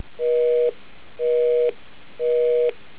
busy signal, when you pick up the handset.
busy.au